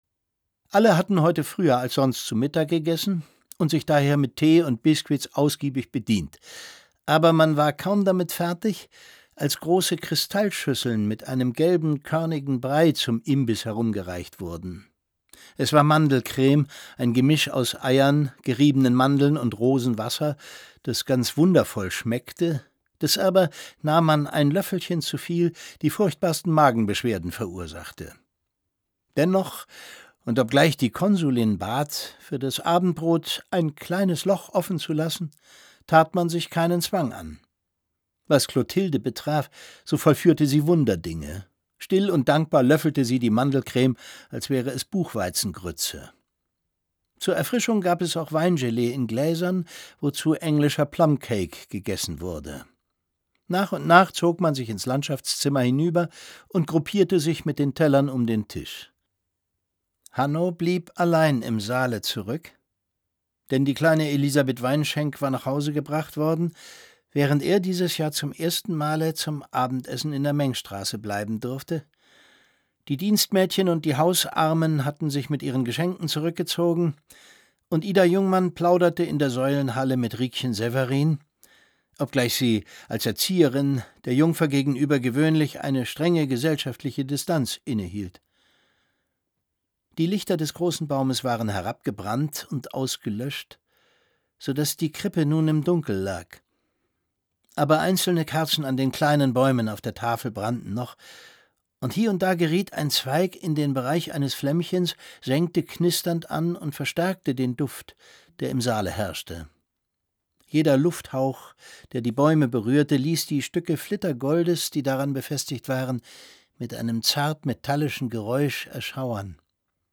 ~ Lesungen Podcast